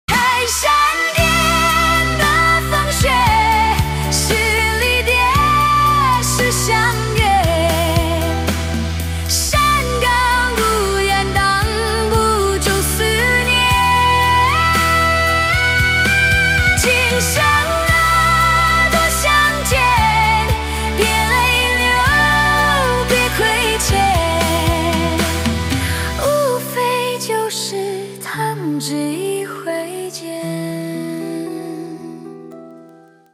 MP3铃声